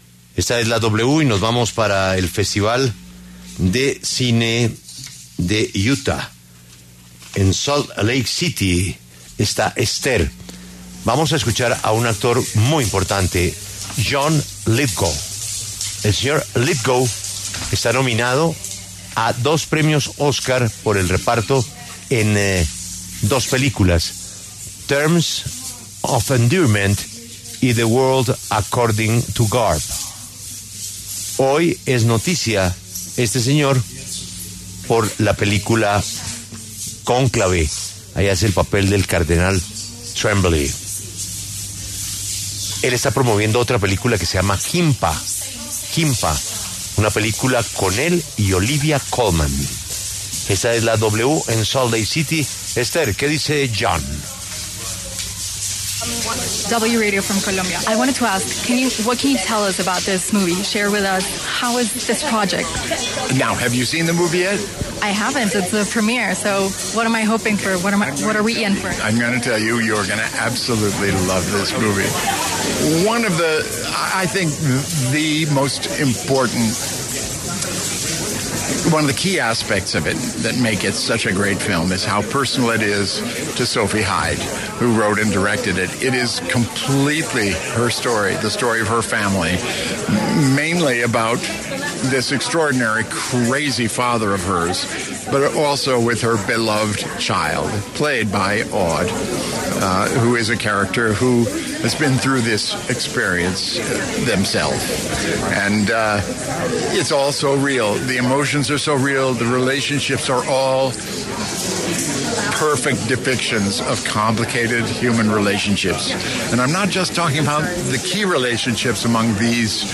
John Lithgow, quien interpreta al cardenal Joseph Tremblay en la película ‘Cónclave’, pasó por los micrófonos de La W para hablar sobre las nominaciones que recibió esta producción en los premios Oscar y su nueva película Jimpa.